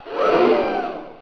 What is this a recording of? CROWD GASP: crowd_gasp.ogg